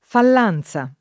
fall#nZa] o fallenza [fall$nZa] s. f. — ant. provenzalismo per «errore; mancanza»: per l’altrui fallanza [per l altr2i fall#nZa] (Dante); a purgar la fallenza [a ppurg#r la fall$nZa] (Boccaccio)